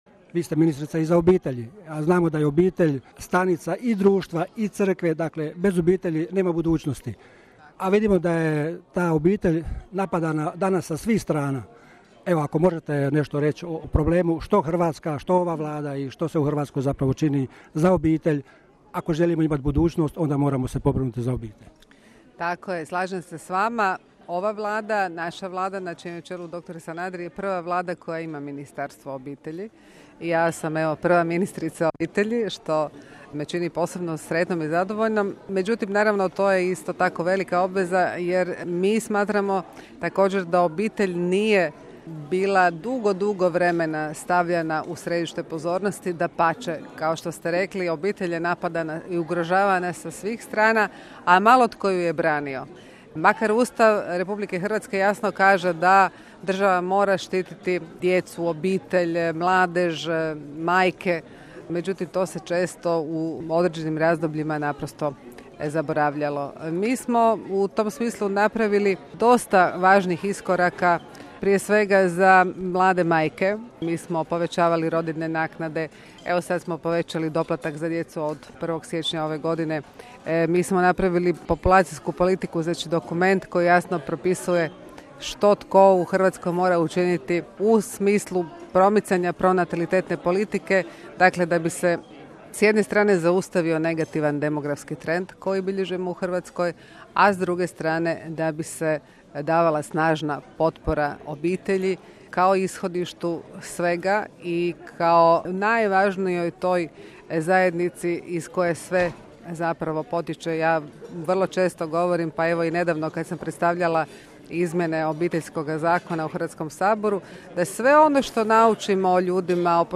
Razgovor s Jadrankom Kosor
O stanju obitelji u Hrvatskoj, naš je glavni urednik razgovarao s podpredsjednicom Vlade i ministricom obitelji Jadrankom Kosor, prigodom njezina nedavna boravka u Rimu.